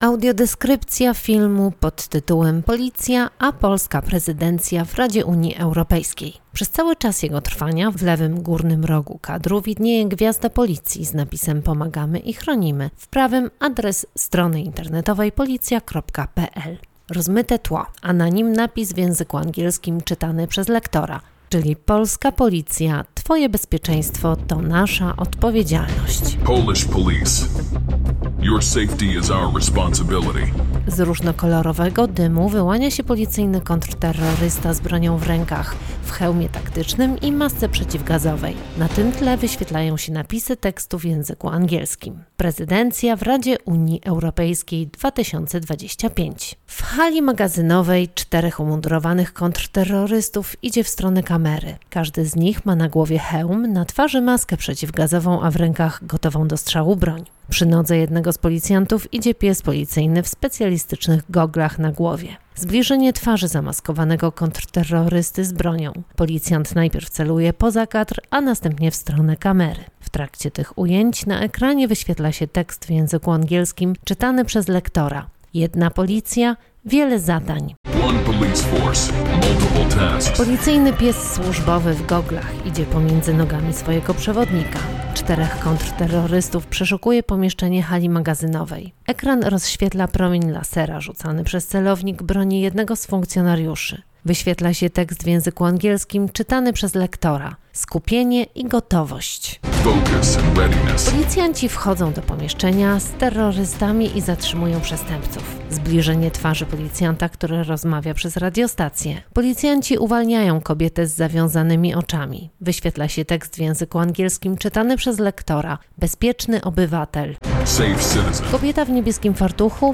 Audiodeskrypcja do filmu (.mp3, 9,99 MB)